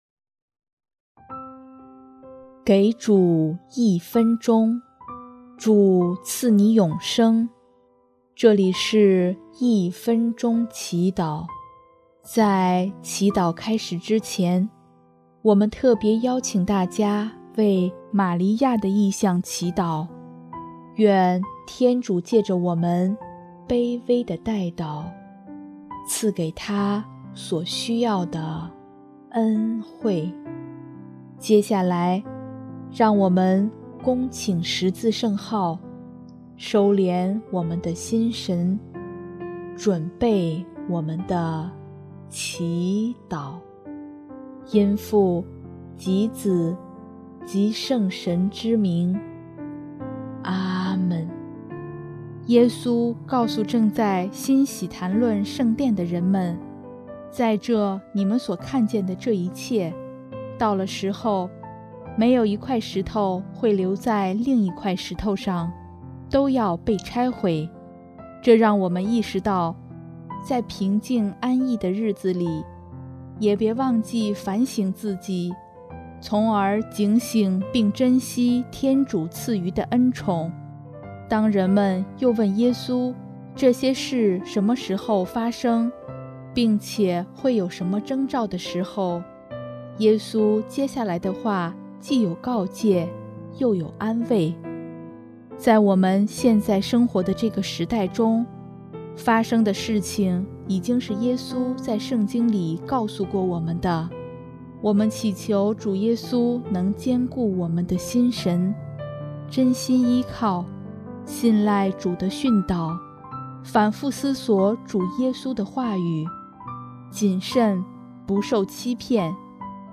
音乐： 主日赞歌《归途》